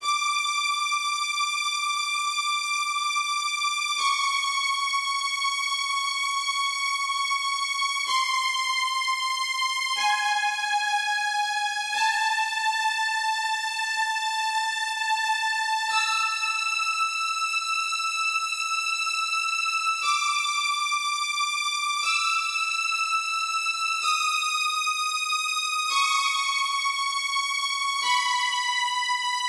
6. Trilha Sonora